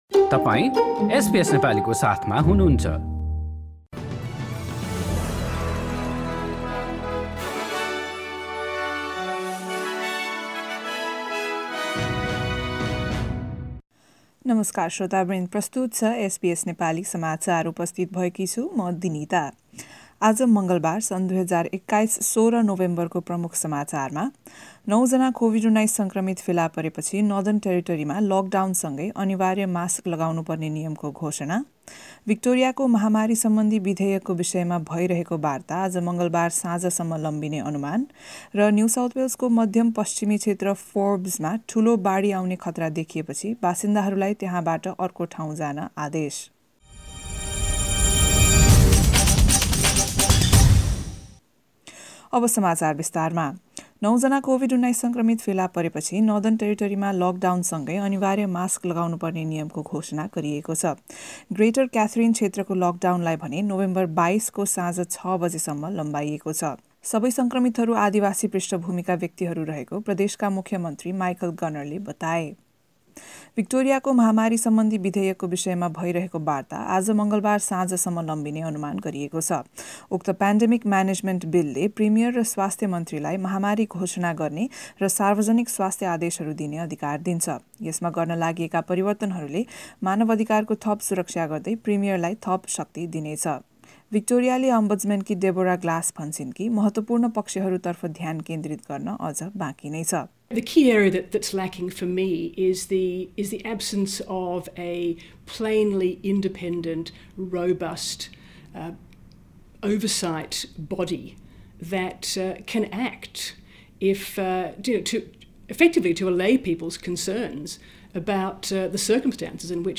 एसबीएस नेपाली अस्ट्रेलिया समाचार: मंगलबार १६ नोभेम्बर २०२१